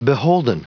Prononciation du mot beholden en anglais (fichier audio)
Prononciation du mot : beholden